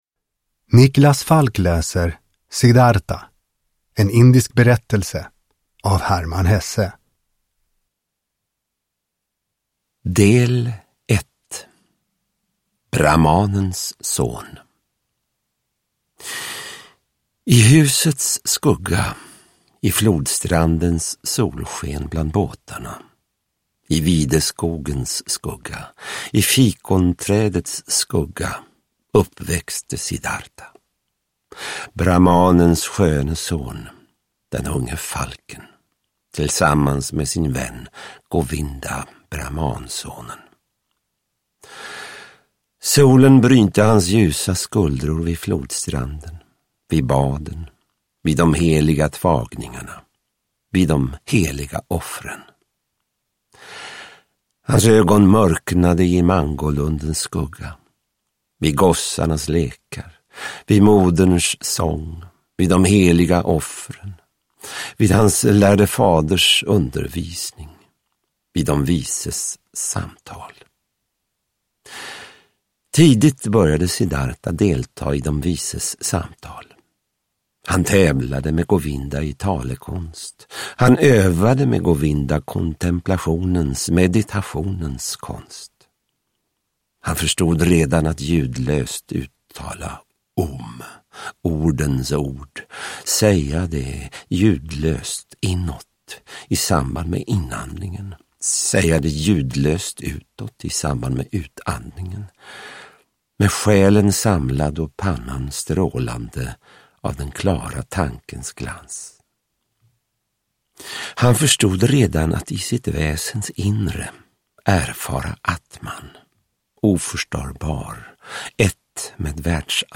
Siddhartha – Ljudbok – Laddas ner